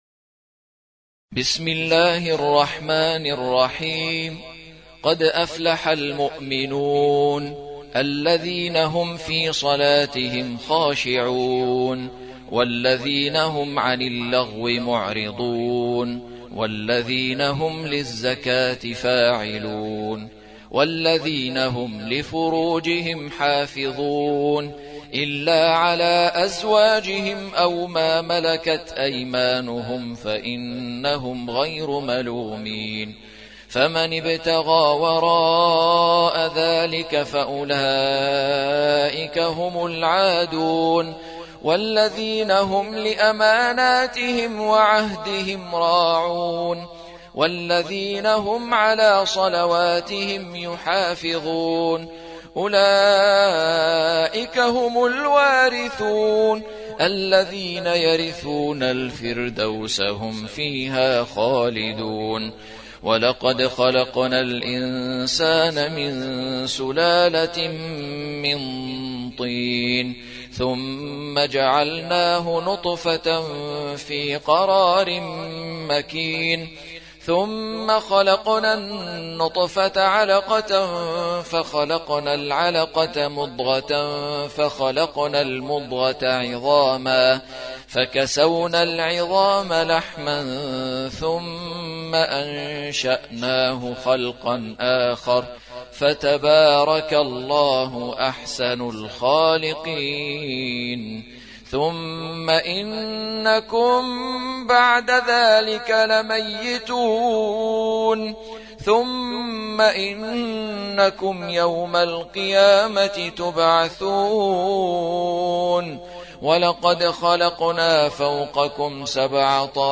23. سورة المؤمنون / القارئ
موقع يا حسين : القرآن الكريم 23.